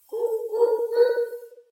mobs_chicken.ogg